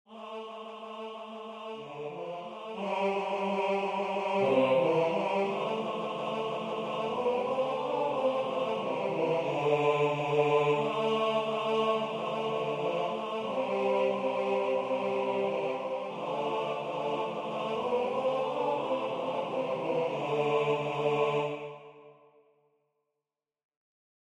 MP3 rendu voix synth.